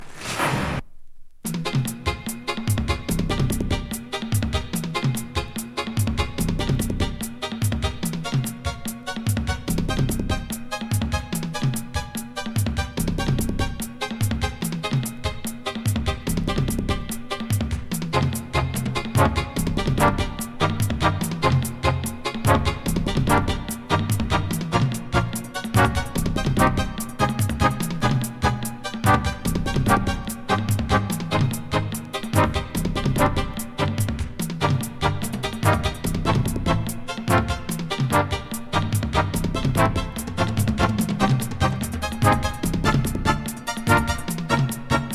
ハイテンション・エレクトロニクスA-1、ちょいステッパーぽい？A-3！